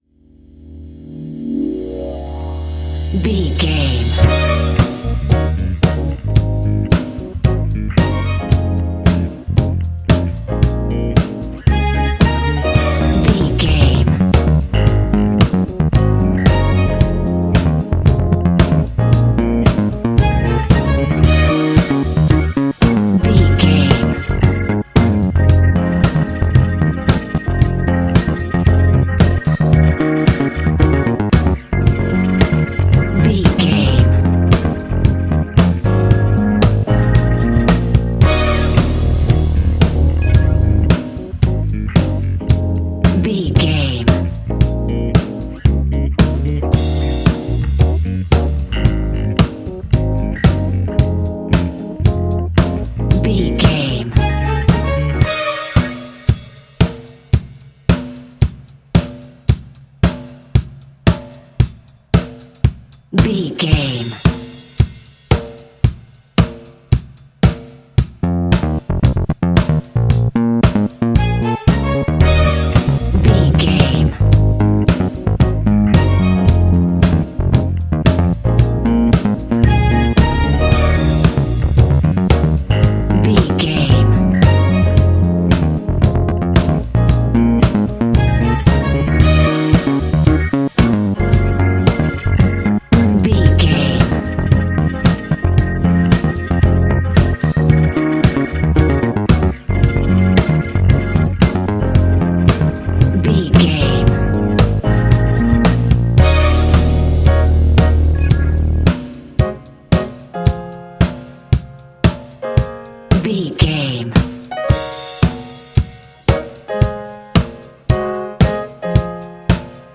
Ionian/Major
D♯
house
electro dance
synths
techno
trance
instrumentals